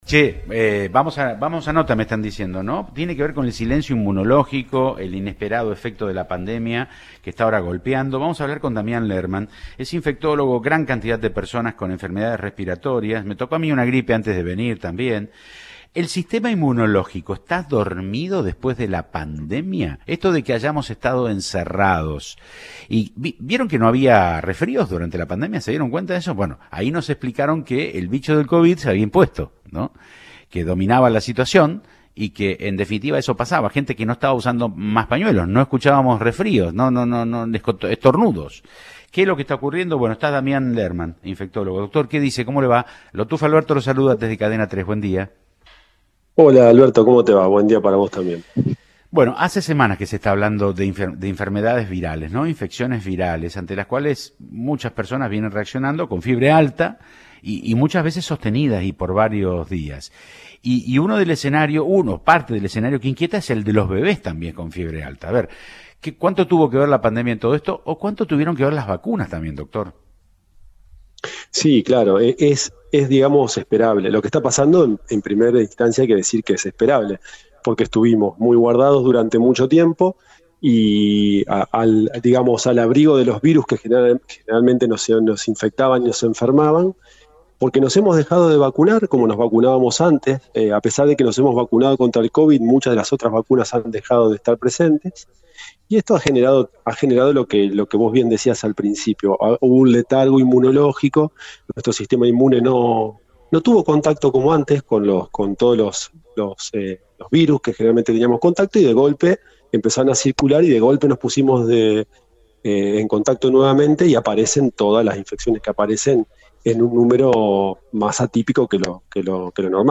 infectólogo